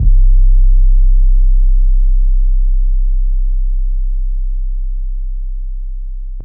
Que808_YC.wav